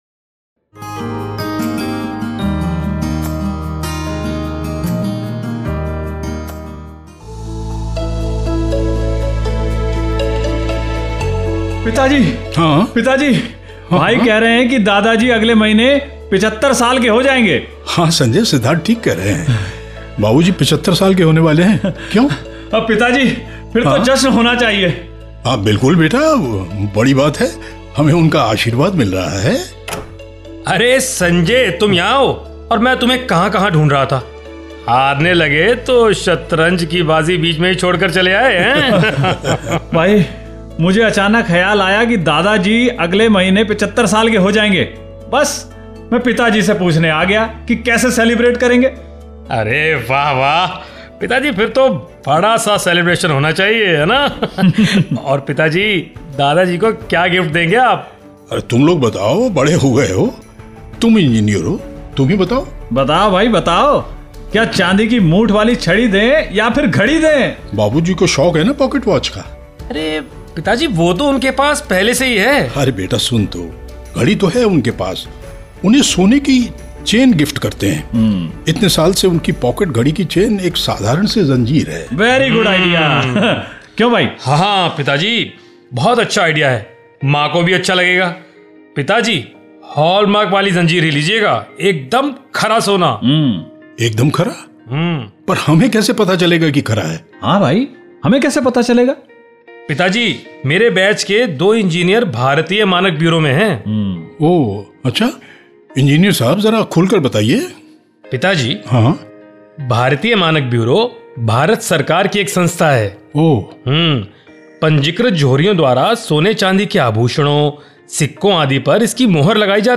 Skit on promotion of Hallmark.